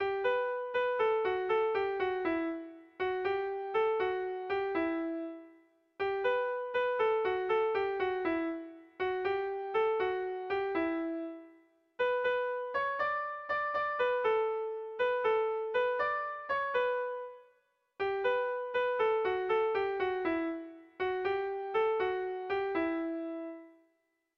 Sentimenduzkoa
Zortziko txikia (hg) / Lau puntuko txikia (ip)
AABA